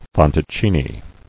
[fan·toc·ci·ni]